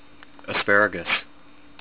as-PARE-uh-gus